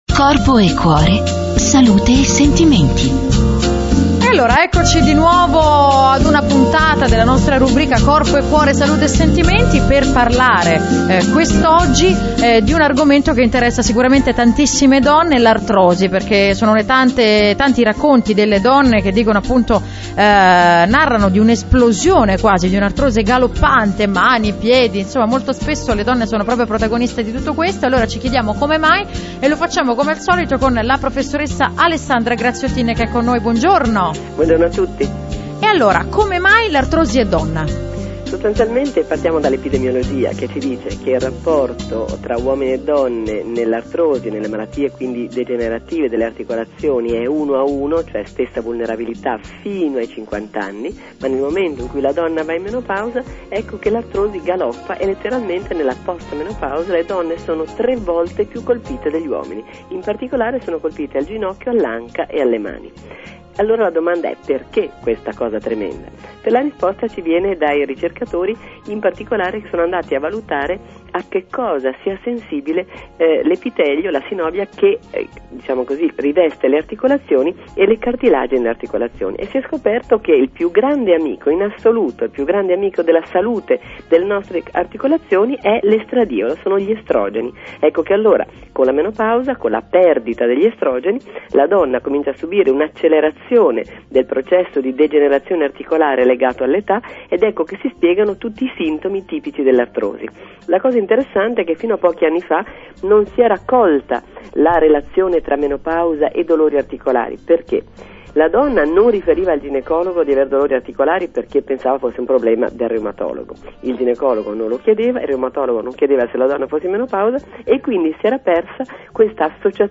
Sintesi dell'intervista e punti chiave